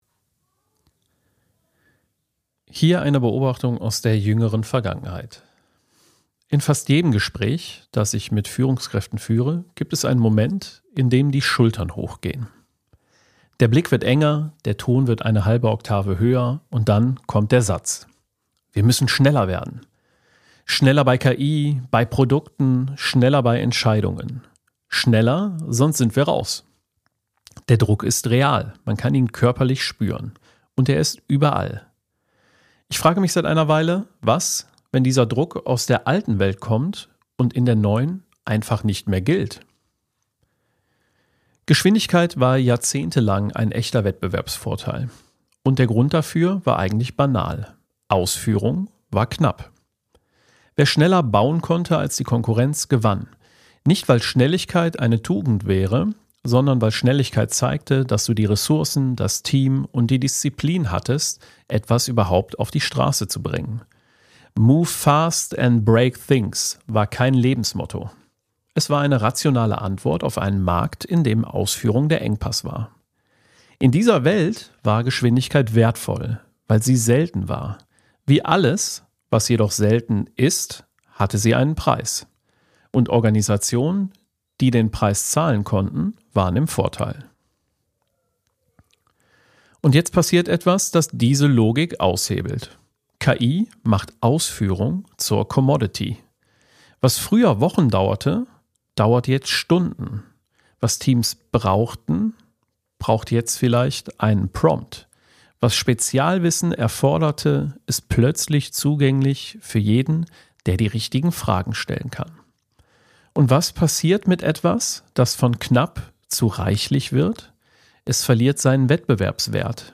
Kein Jingle, kein Small Talk.